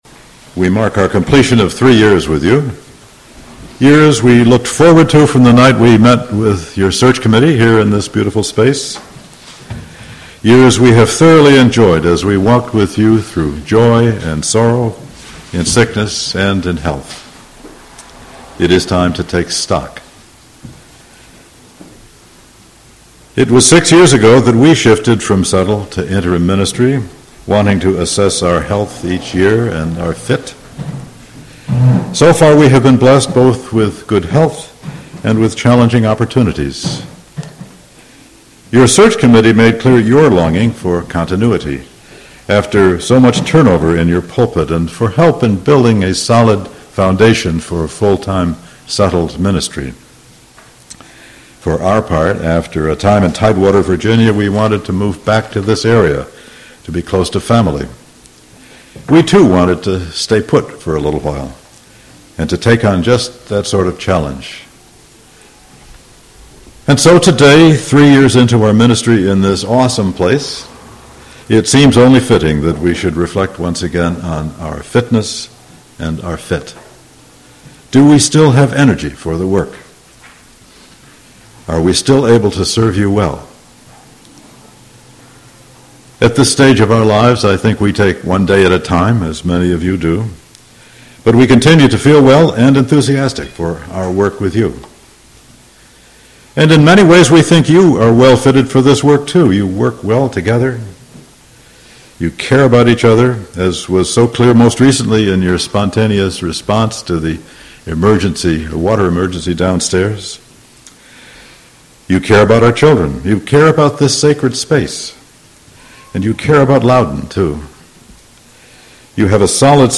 This sermon excerpts document a reflection on a three-year interim ministry.